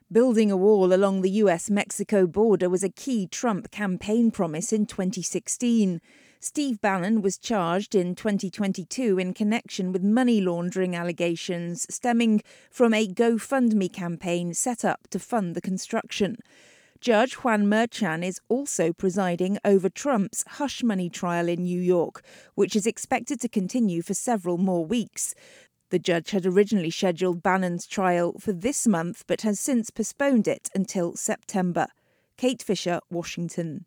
A former senior advisor to Donald Trump, Steve Bannon, is set to appear in court in New York on Wednesday over allegations that he defrauded people who donated to the campaign to build a wall along the US Mexico border. Bannon has pleaded not guilty to charges of money laundering, conspiracy and scheming to defraud in connection with $15 million in donations. Our US correspondent